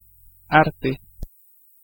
Ääntäminen
Vaihtoehtoiset kirjoitusmuodot (vanhentunut) skil Synonyymit ability talent split ease command competence proficiency expertness skillfulness skilfulness Ääntäminen US : IPA : [ˈskɪɫ] Tuntematon aksentti: IPA : /skɪl/